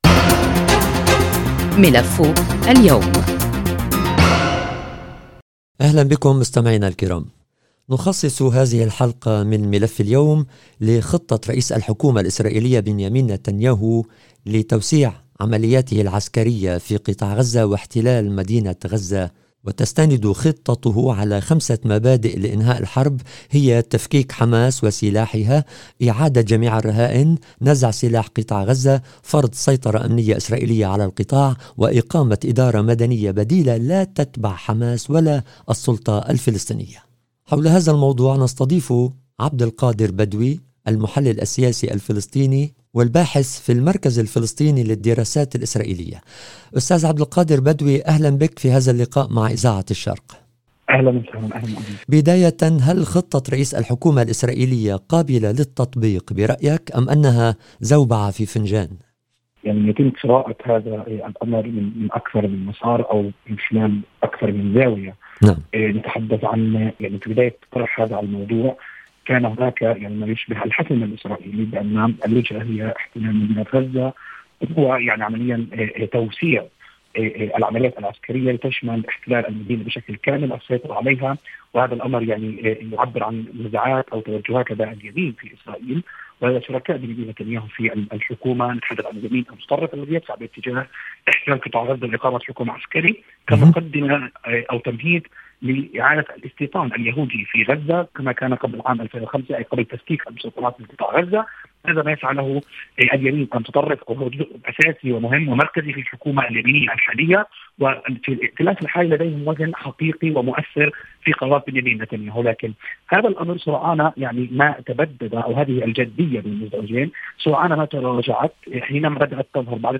وفي معرض حديثه إلى إذاعة الشرق، قال الباحث الفلسطيني أنه يرى ان اعلان نتانياهو عن خطة لاحتلال مدينة غزة قد يكون تمهيداً لعودة الاستيطان في غزة وارضاء للوزراء المتطرفين تفادياً لخروجهم من الحكومة وايضاً ممارسة الضغط على الجانب الاميركي والدول العربية من اجل التوصل الى اتفاق شامل. للاستماع للحوار